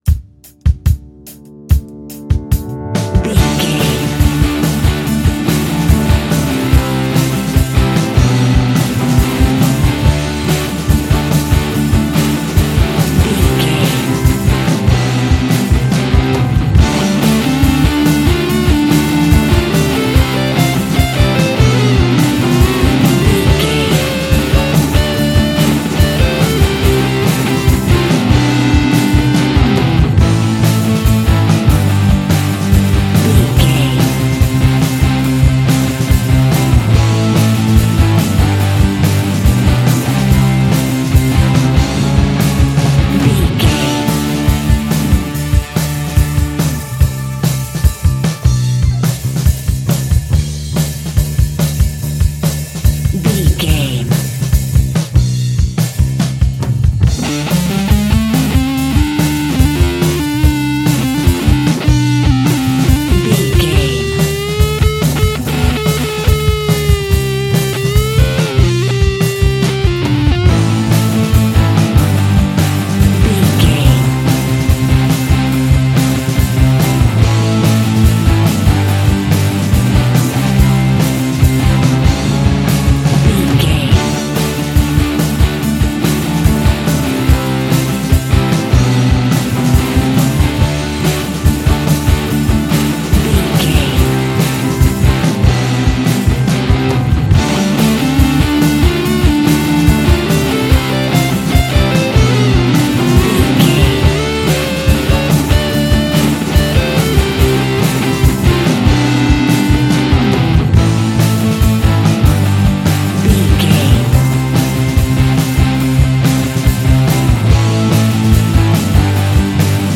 Aeolian/Minor
groovy
powerful
electric guitar
bass guitar
drums
organ